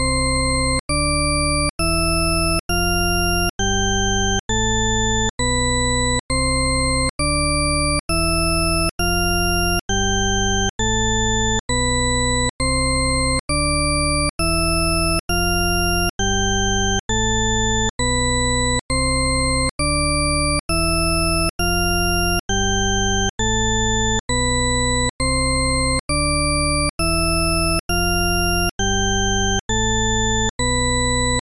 Cette gamme est entièrement synthétique.
Shepard_scale_diatonic_C.ogg.mp3